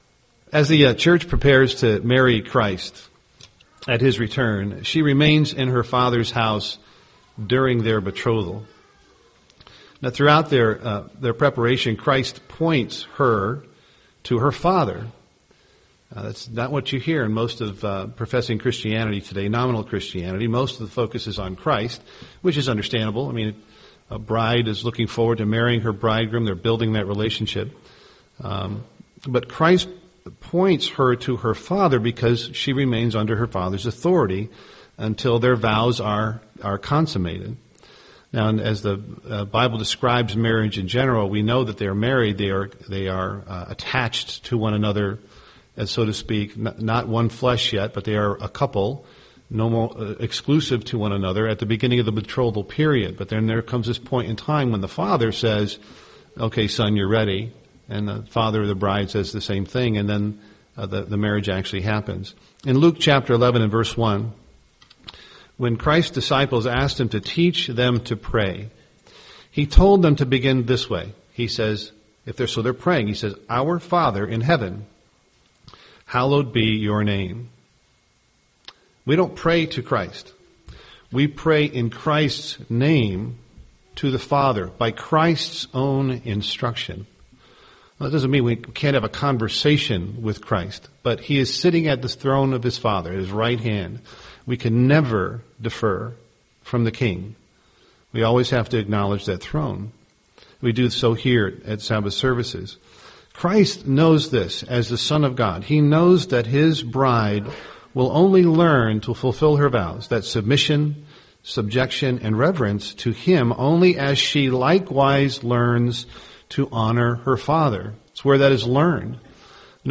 UCG Sermon image of god Studying the bible?